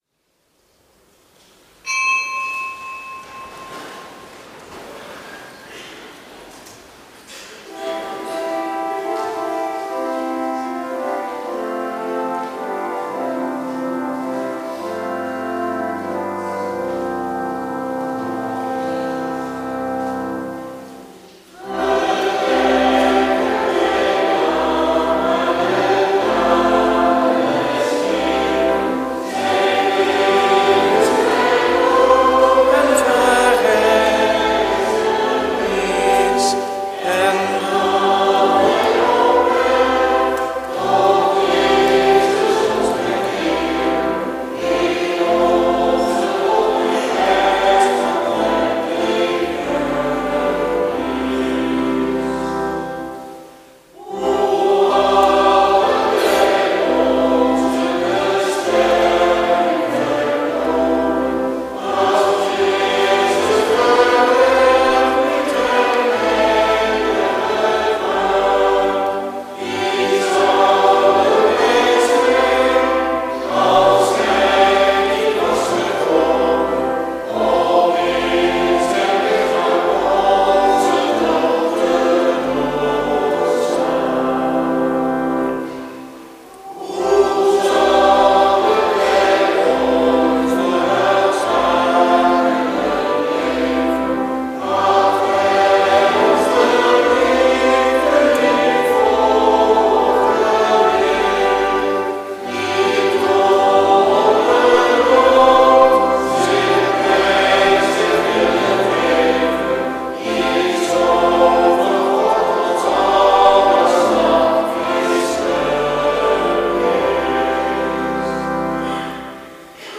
Eucharistieviering beluisteren vanuit de Joannes de Doper te Katwijk (MP3)